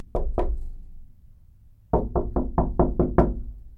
جلوه های صوتی
دانلود صدای در چوبی 5 از ساعد نیوز با لینک مستقیم و کیفیت بالا
برچسب: دانلود آهنگ های افکت صوتی اشیاء دانلود آلبوم صدای کوبیدن در چوبی از افکت صوتی اشیاء